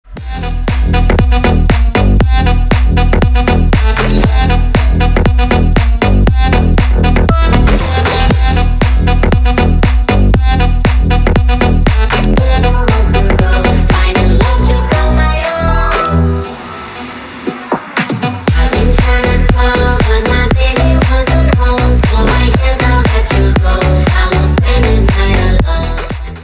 играет Громкие звонки, звучные рингтоны🎙